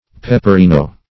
Search Result for " peperino" : The Collaborative International Dictionary of English v.0.48: Peperine \Pep"e*rine\, Peperino \Pep`e*ri"no\, n. [It. peperino, L. piper pepper.